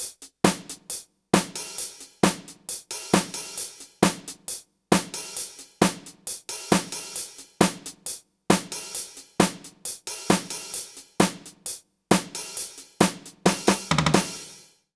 British ROCK Loop 134BPM (NO KICK).wav